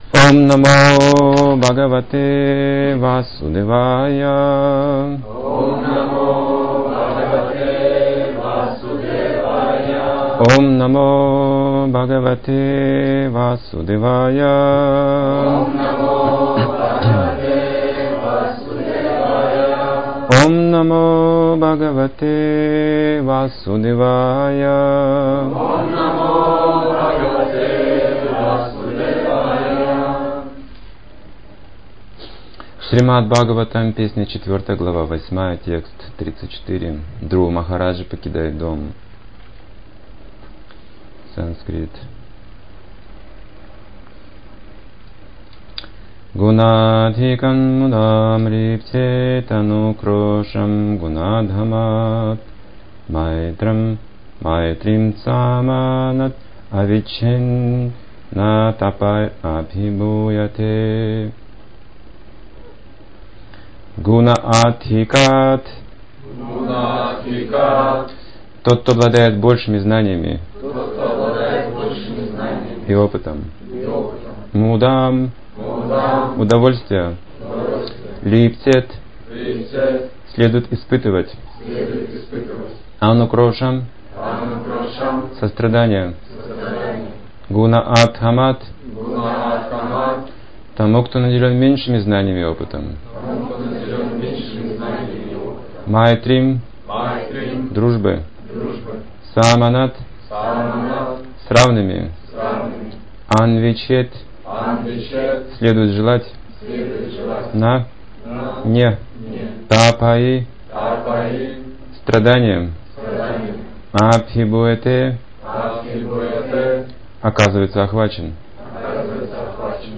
Темы, затронутые в лекции: 3 правила духовной жизни, позволяющие достичь совершенства Почему люди избегают наставлений садху?